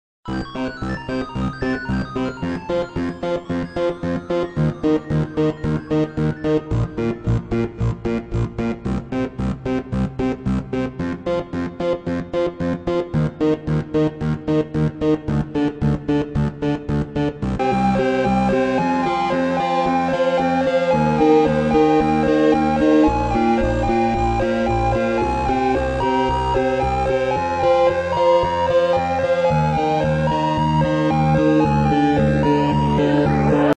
Instrument 2
Also mal abgsehen davon, dass das Melodieinstrument in beiden fällen extrem unsauber klingt (vielleicht ist das auch gewollt?), würde ich auf Synthesizer/Keyboard tippen.
Naja, stimmt, hatte mir echt nur das erste angehört, aber das 2. Beispiel klingt doch garnicht mehr nach Theremin...